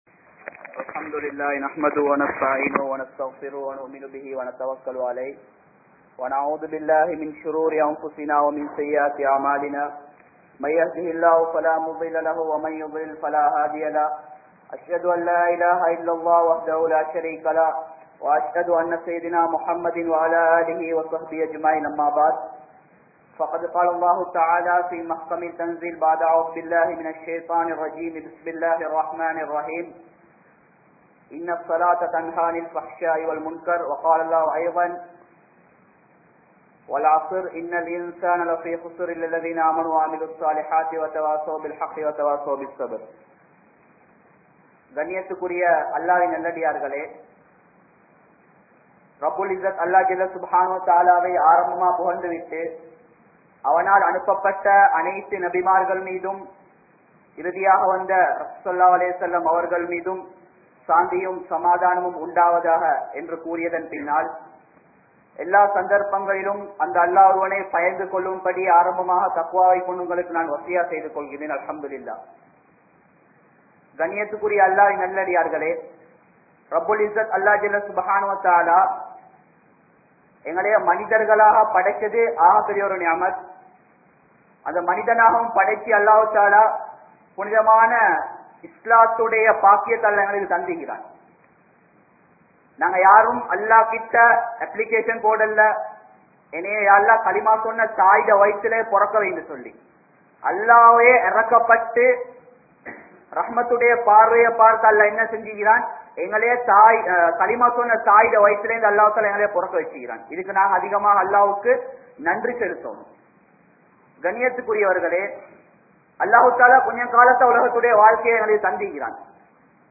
Tholuhai Ilaathavanin Nilai (தொழுகை இல்லாதவனின் நிலை) | Audio Bayans | All Ceylon Muslim Youth Community | Addalaichenai